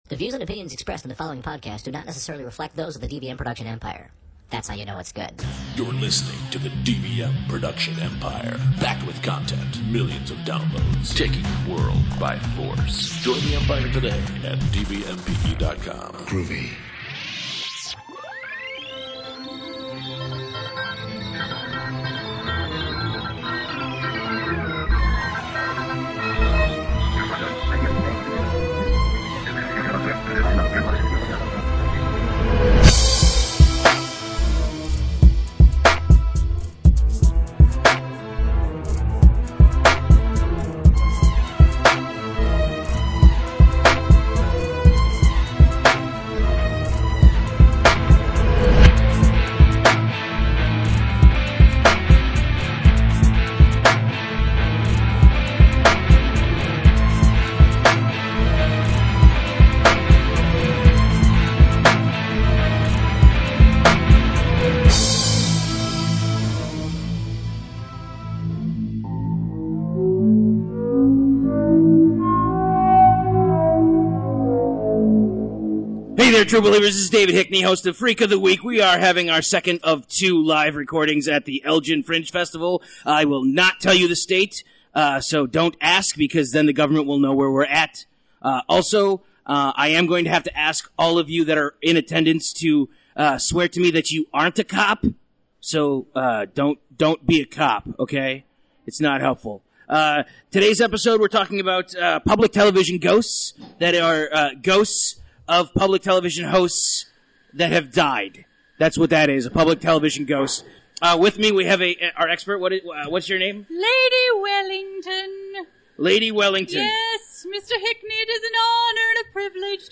204 – PBS Ghosts – Live from EFF
Our finale from the Elgin Fringe Festival saw us exploring ghosts from public television. What do they want and Will we get a tote bag And just how many people were Bob Ross.